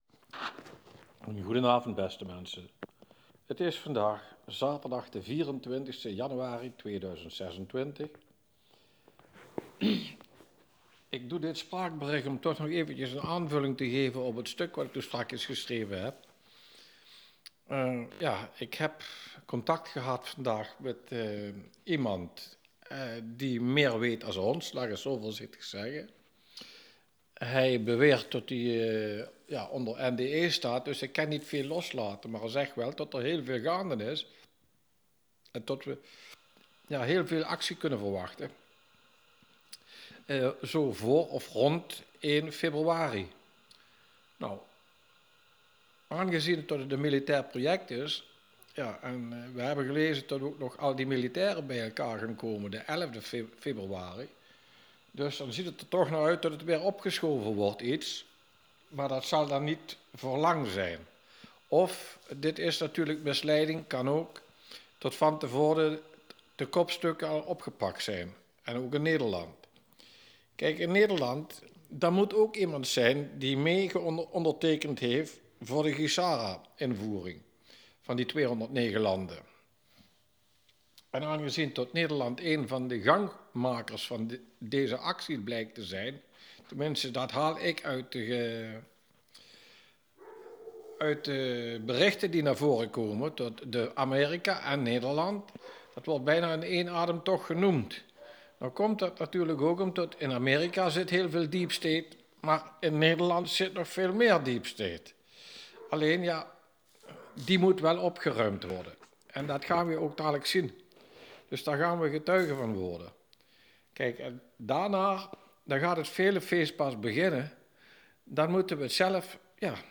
AUDIOBERICHT